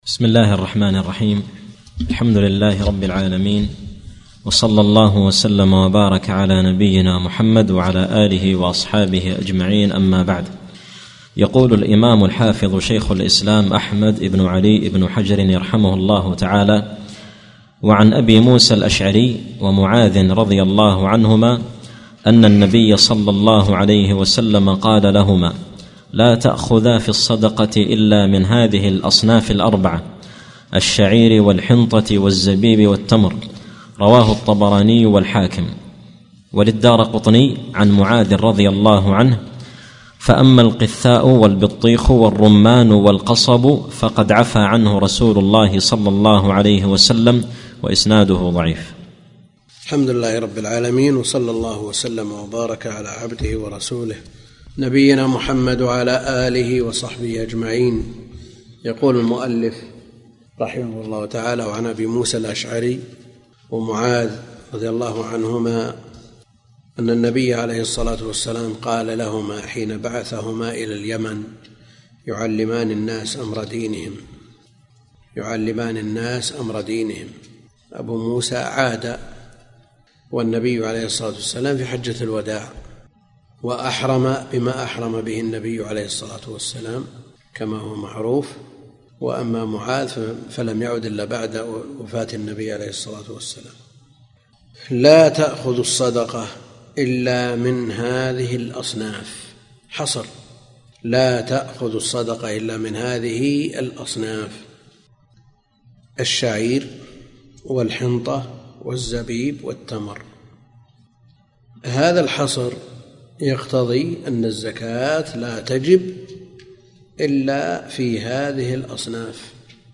سلسلة محاضرات صوتية